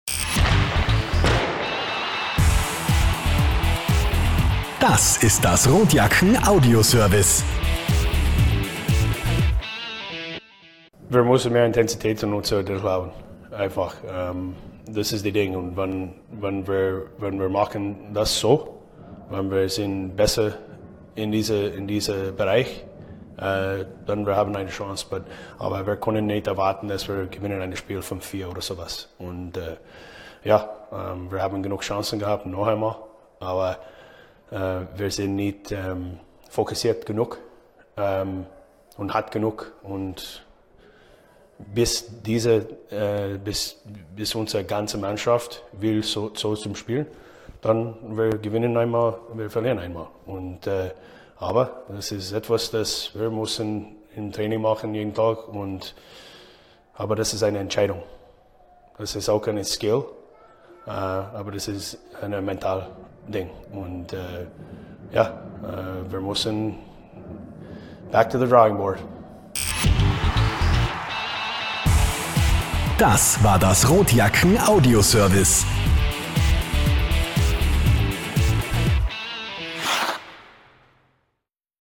Post Game-Kommentar
Heidi Horten-Arena, Klagenfurt, AUT, 4.067 Zuschauer